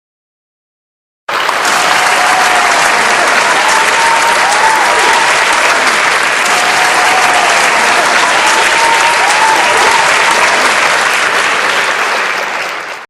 KGvnypQycWc_Bravo-aplausos-clapping.opus